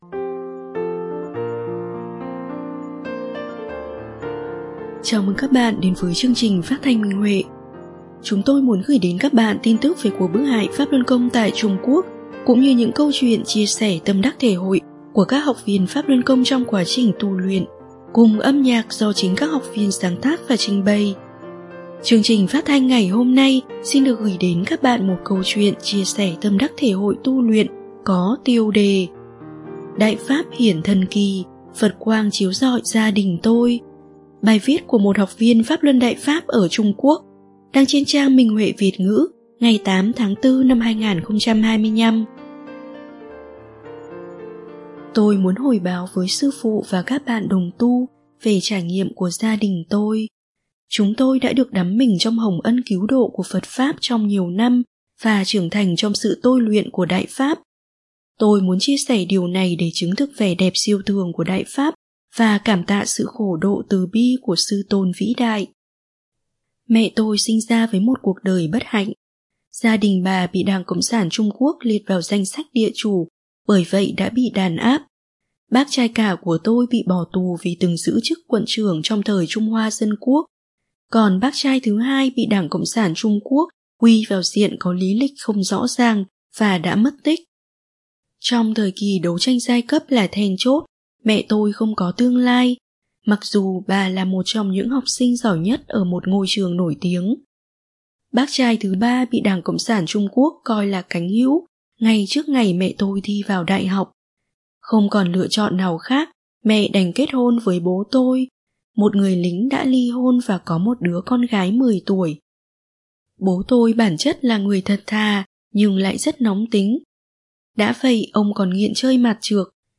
Chúng tôi muốn gửi đến các bạn thông tin về cuộc bức hại Pháp Luân Công tại Trung Quốc cũng như những câu chuyện chia sẻ tâm đắc thể hội của các học viên trong quá trình tu luyện, cùng âm nhạc do chính các học viên sáng tác và trình bày.
Chương trình phát thanh số 1456: Bài viết chia sẻ tâm đắc thể hội trên Minh Huệ Net có tiêu đề Đại Pháp hiển thần kỳ, Phật quang chiếu rọi gia đình tôi, bài viết của đệ tử Đại Pháp tại Trung Quốc.